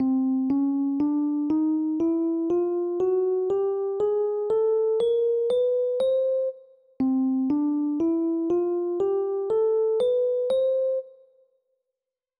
C-Chromatic-and-C-Major-Scale.wav